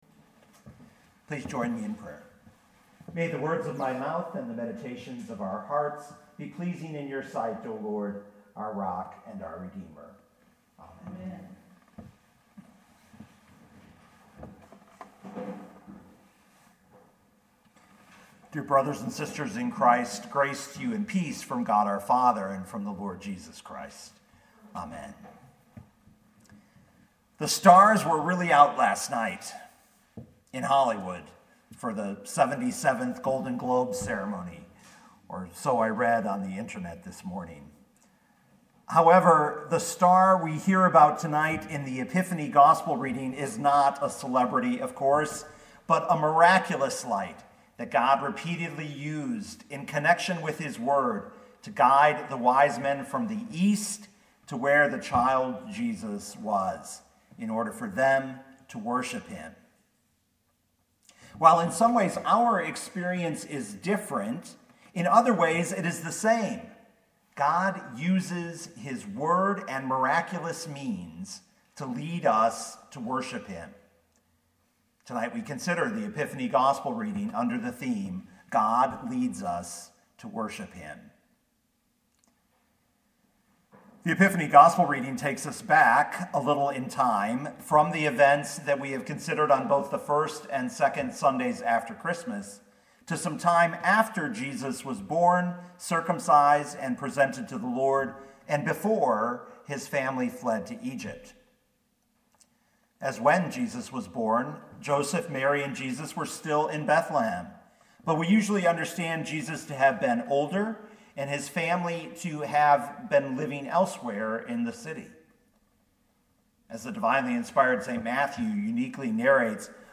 2020 Matthew 2:1-12 Listen to the sermon with the player below, or, download the audio.